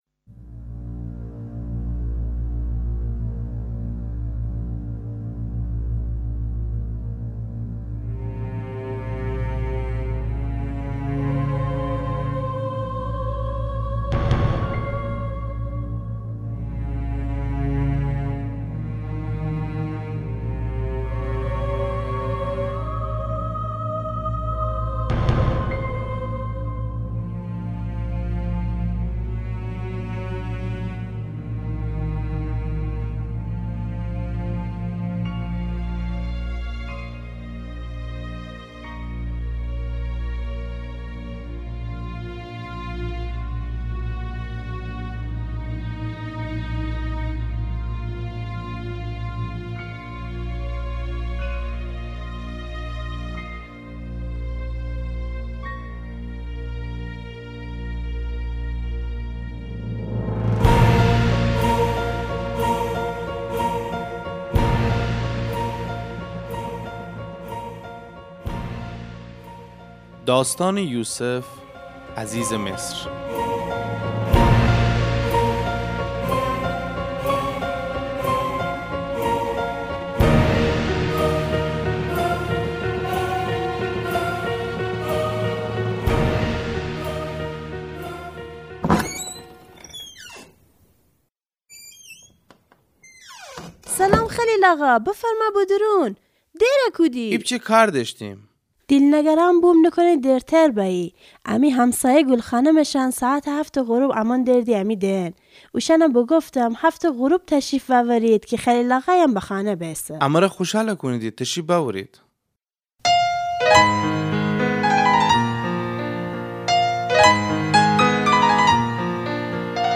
Das Leben von Joseph Hörspiel | Gilak Media